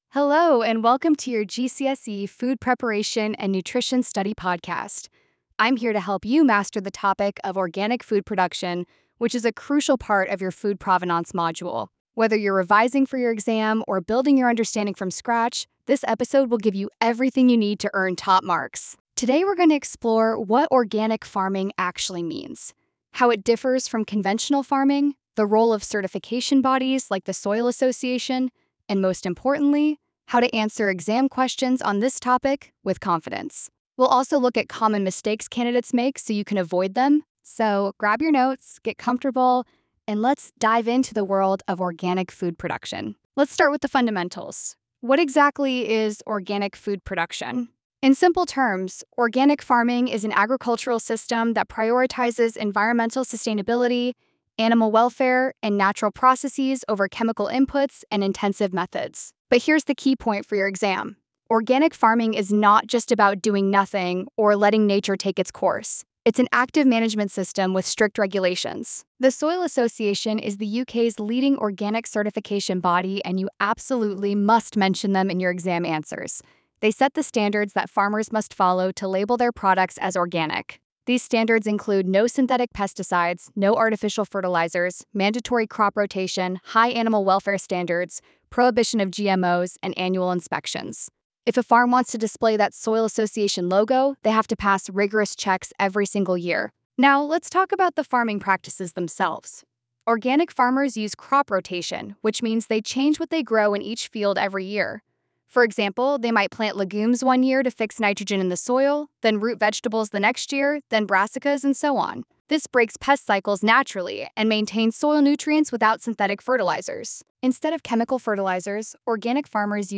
Speaker: Female educator, warm and engaging tone